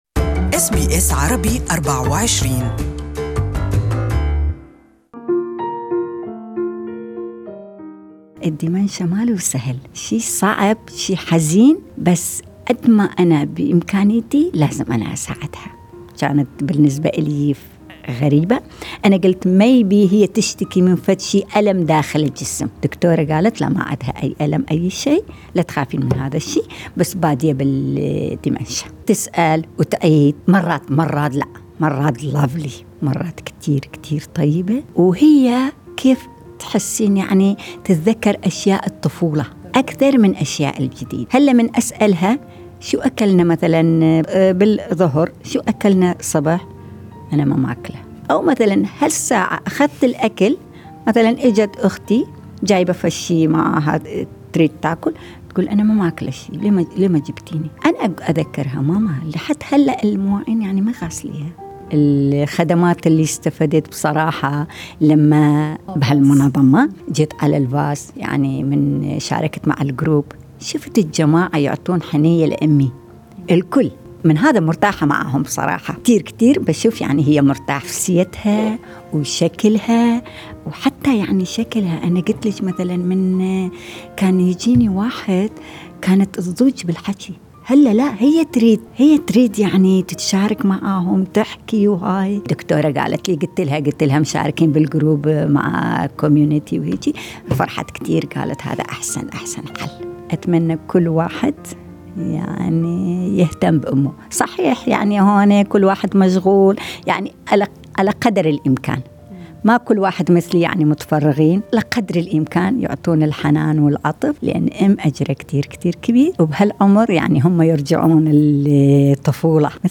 وستستمعون في كل حلقة الى بعض من قصص كبار السن وتجاربهم مع الخدمات التي يستفيدون منها في استراليا لتحسن من نوعية الحياة التي يعيشونها بالإضافة الى بعض من النصائح المقدمة من مسؤولي تقديم الرعاية للمسنين.